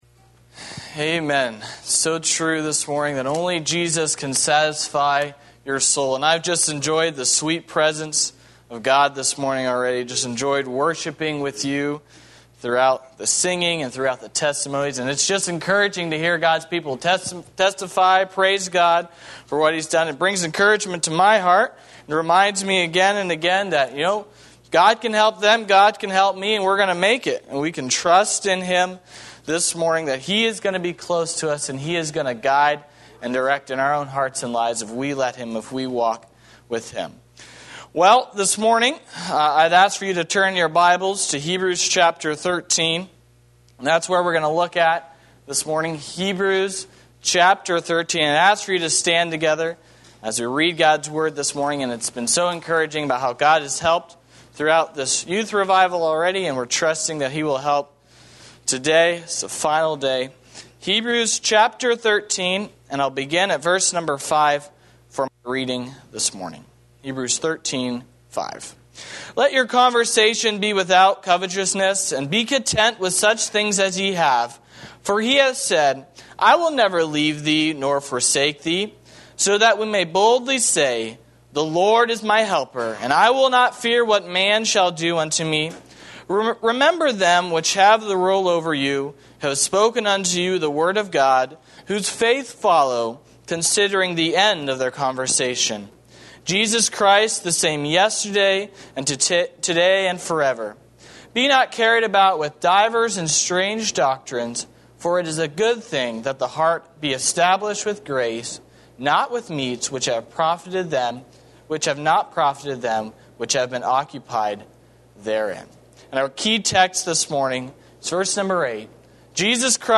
A sermon
Series: Youth Revival 2020